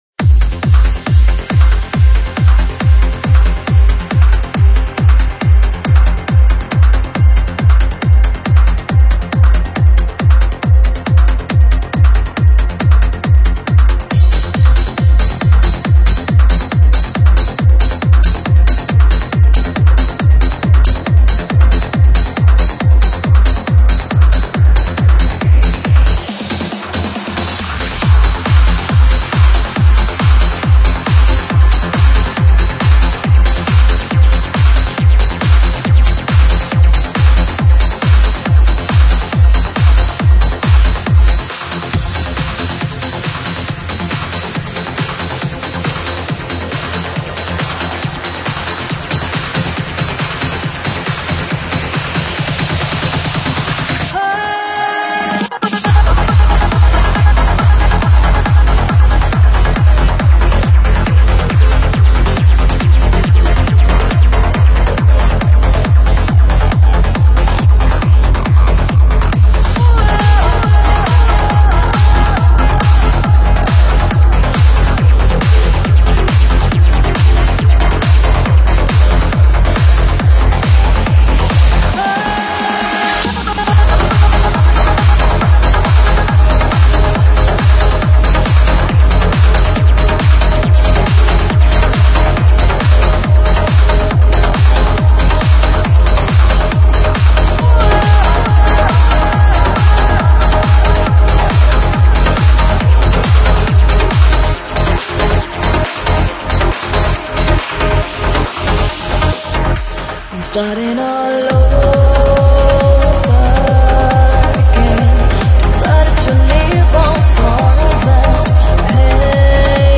Стиль: Vocal Trance / Uplifting Trance